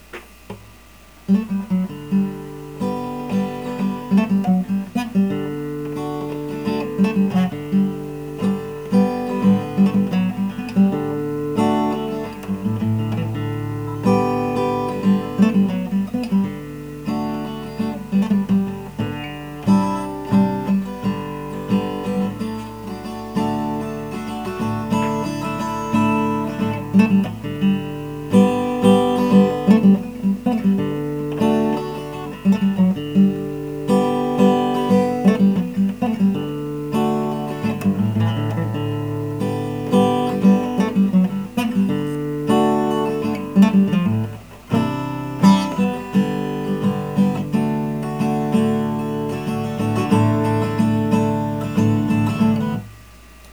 It sounds ok.